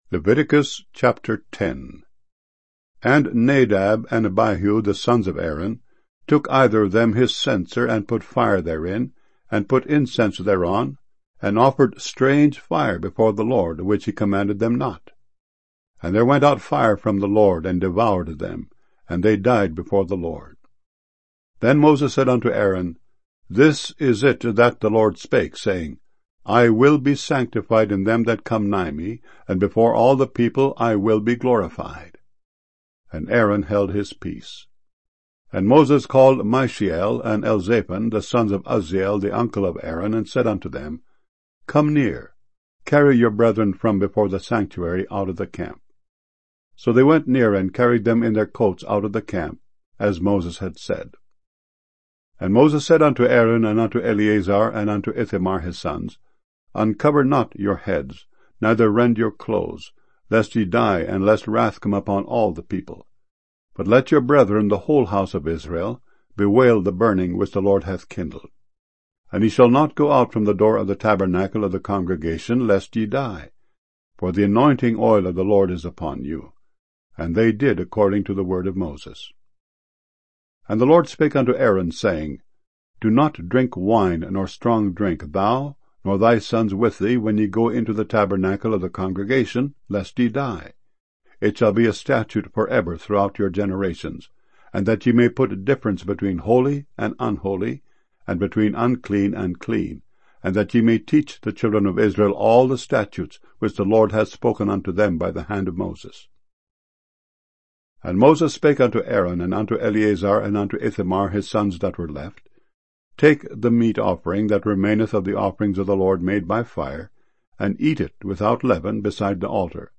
00 Streaming MP3 Audio Bible files mono 32 kbs small direct from wav files